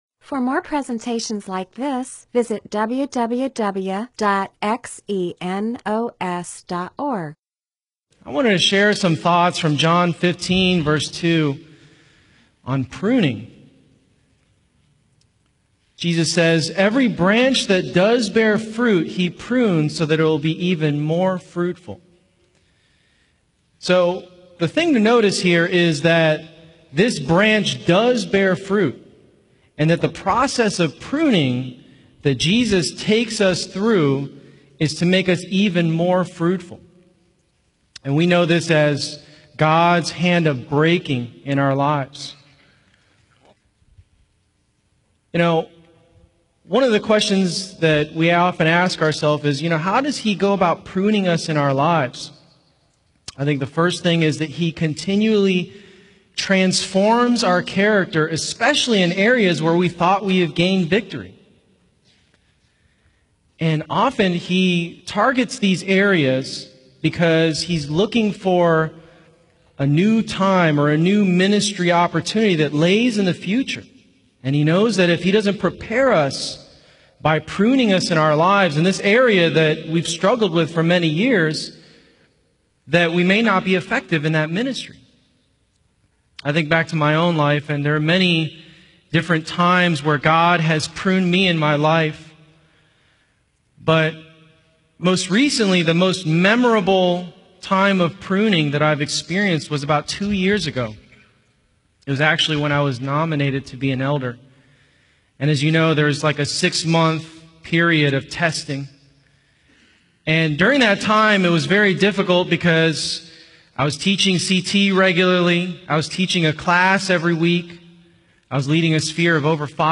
MP4/M4A audio recording of a Bible teaching/sermon/presentation about John 15:2.